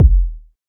Kick Murdah 3.wav